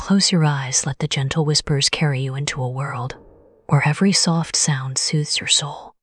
Voix narrative féminine expressive
Synthèse vocale
Narration émotive